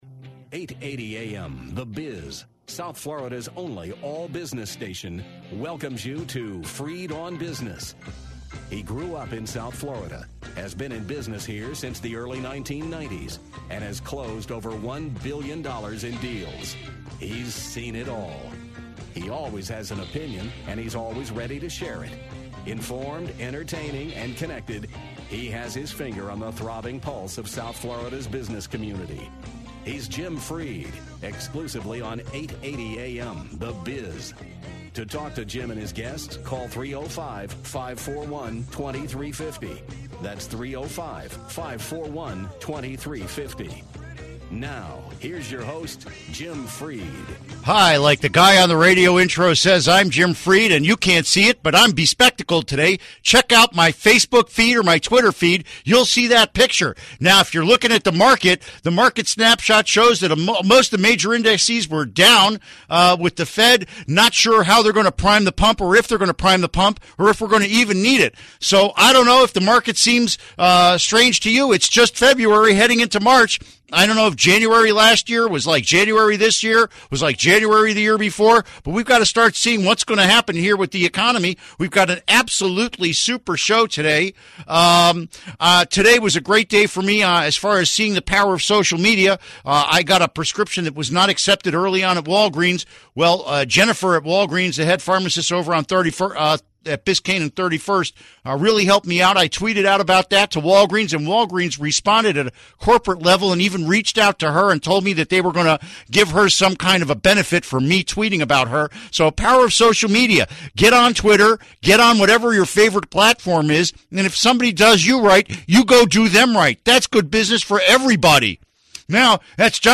Top real estate industry experts discuss market conditions and opportunities in Florida.